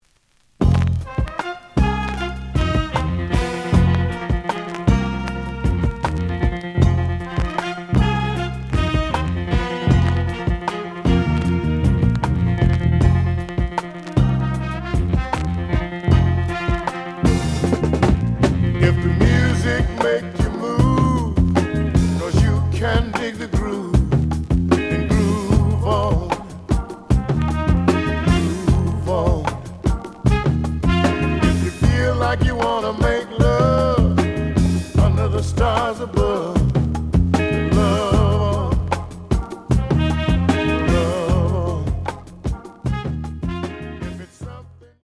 The audio sample is recorded from the actual item.
●Genre: Soul, 70's Soul
Some noise on beginnig of A side.